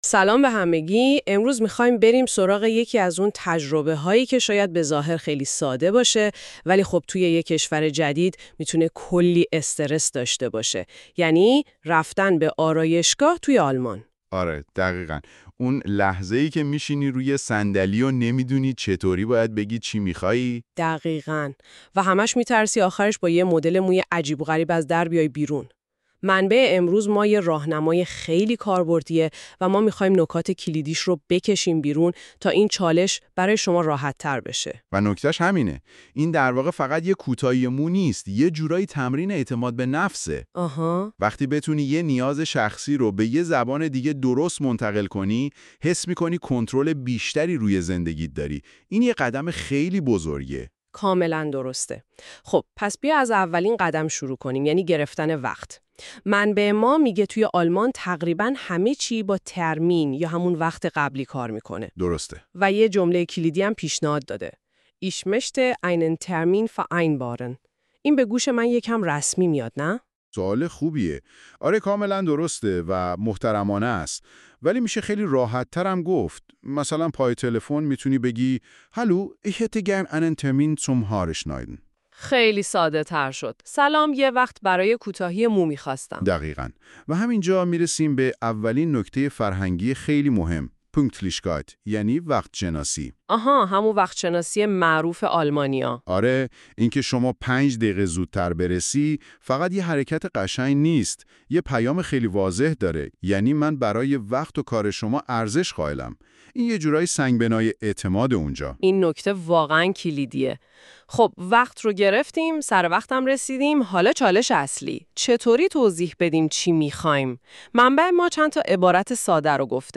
german-conversation-at-the-hairdresser.mp3